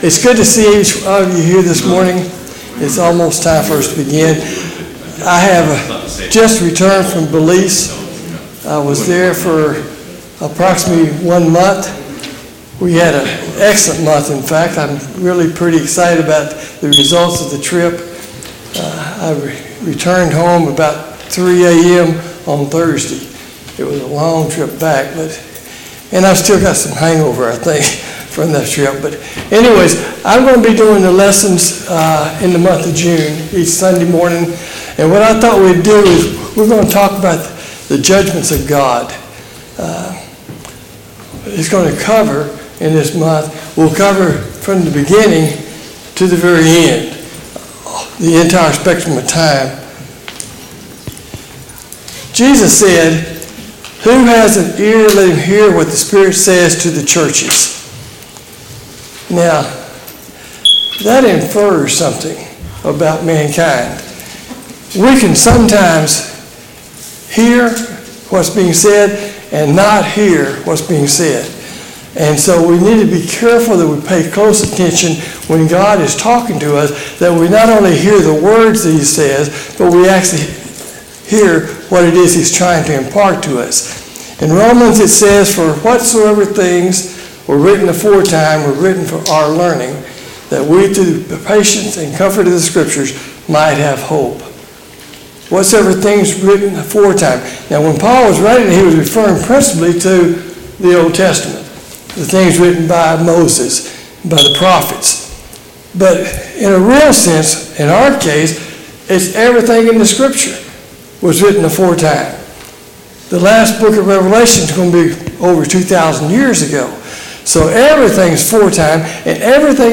Judgements of God Service Type: Sunday Morning Bible Class Download Files Notes Topics: Sin , Temptation